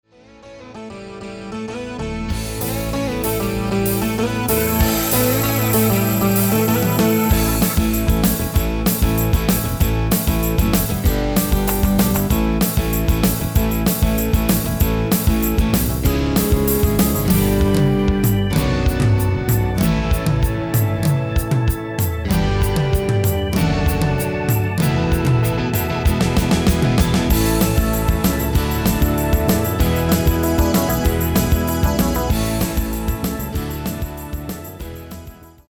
MP3-orkestband gearrangeerd in de stijl van:
Genre: Pop & Rock Internationaal
Toonsoort: C
MP3-orkestband bevat GEEN melodie en/of backingvocals!
File type: 44.1KHz, 16bit Stereo